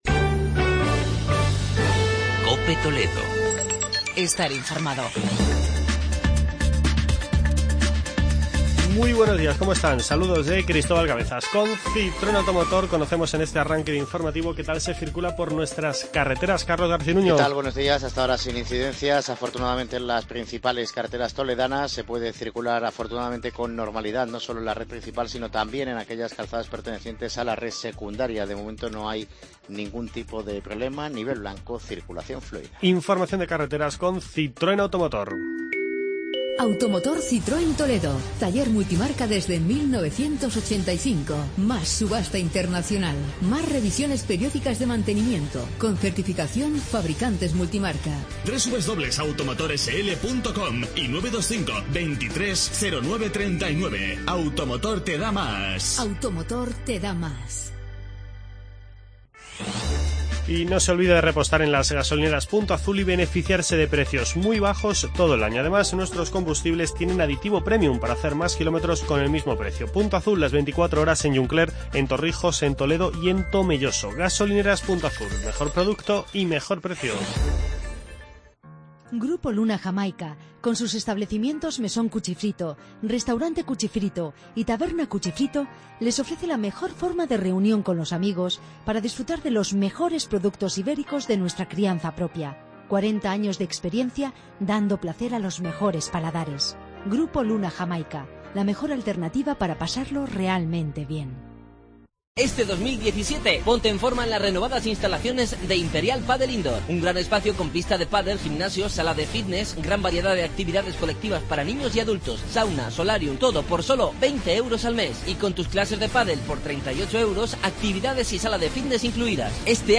Informativo provincial